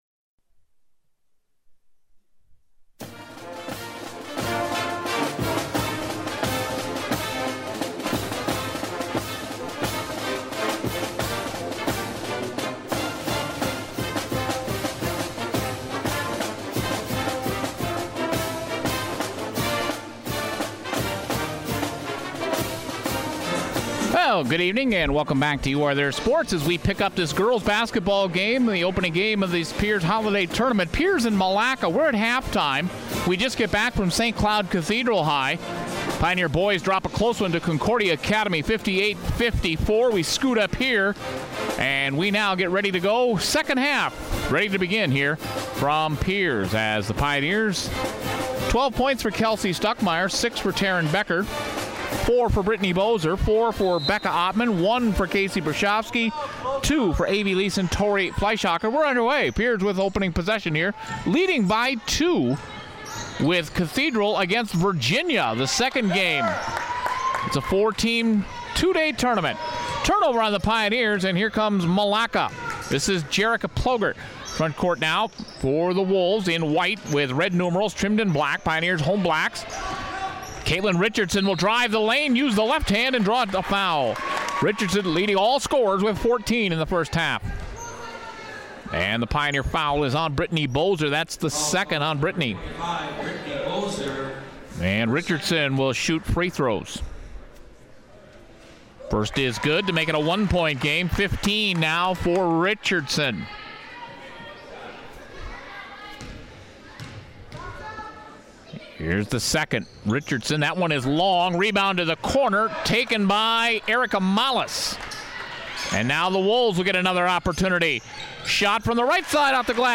Here's the 2nd half of the Pioneers 74-68 loss to the Wolves at the Pierz Holiday Tournament.